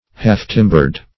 Half-timbered \Half"-tim`bered\ (h[aum]f"t[i^]m`b[~e]rd), a.